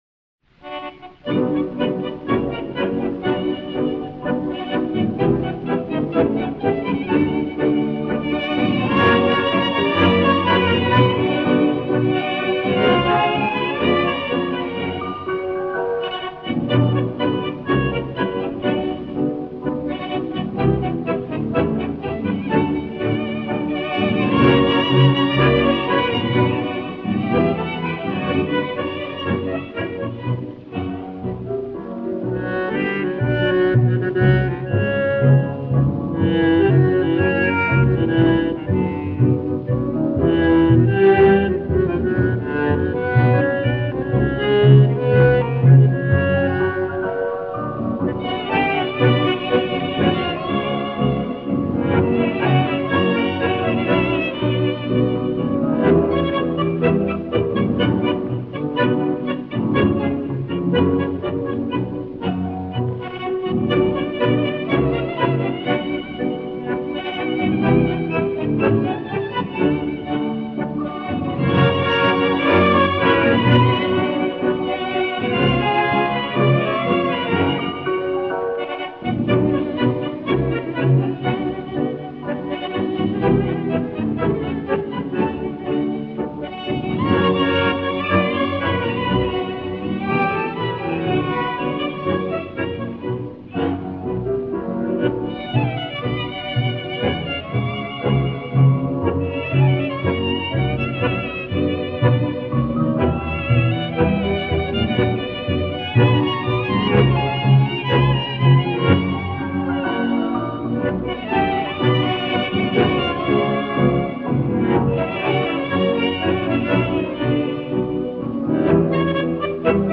Танго
Instrumental